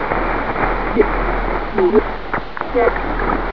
tv_loop1.ogg